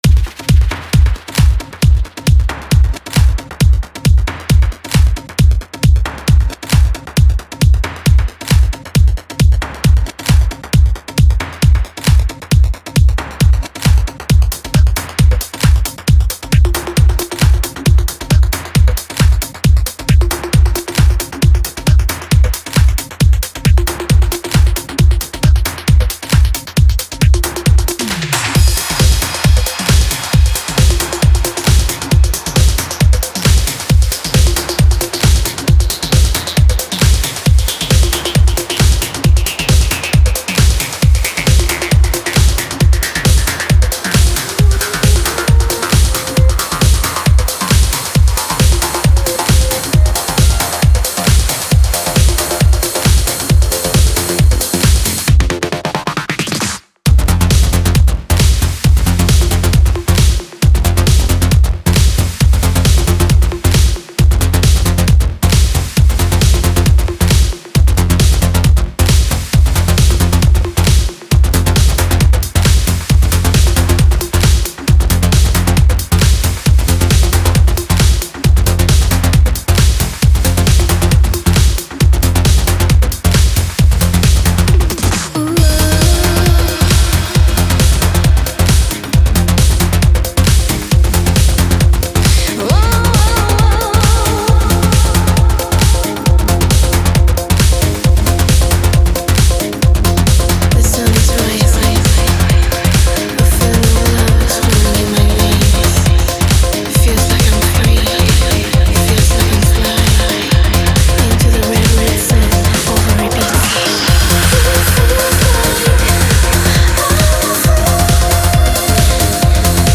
Жанр: Trance
Trance Vocal